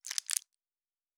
Plastic Foley 11.wav